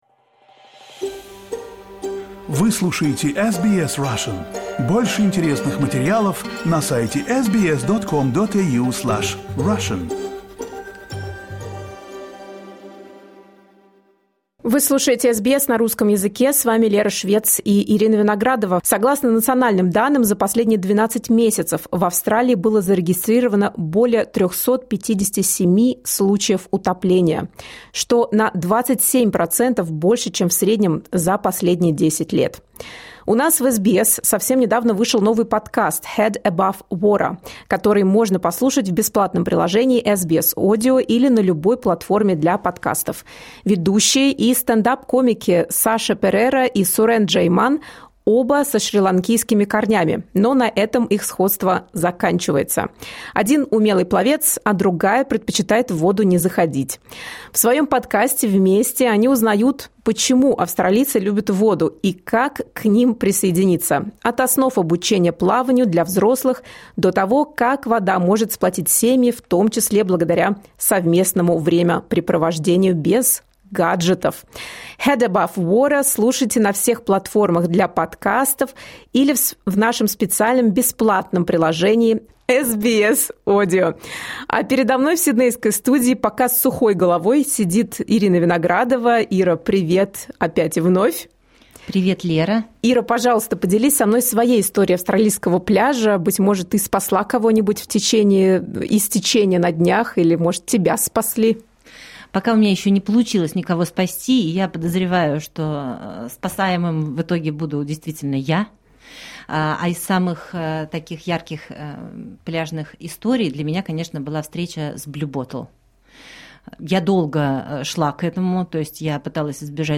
В подкасте использован фрагмент архивного интервью